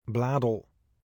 Bladel (Dutch pronunciation: [ˈblaːdəl]